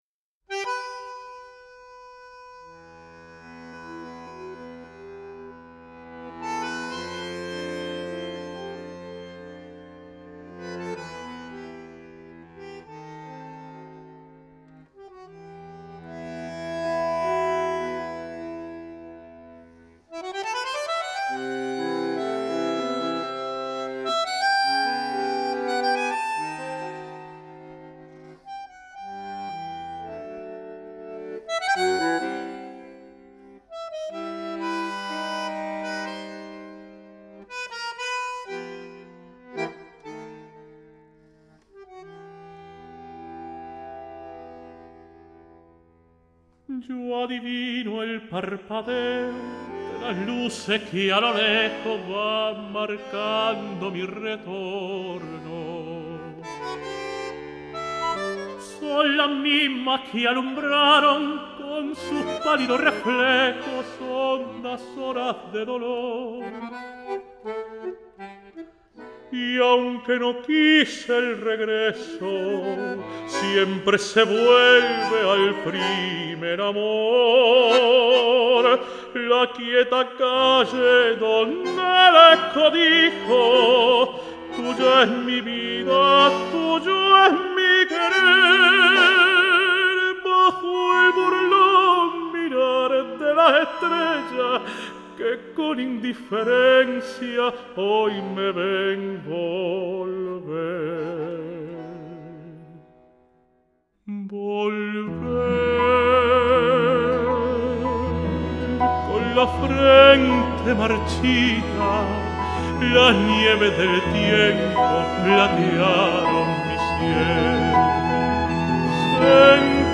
El tenor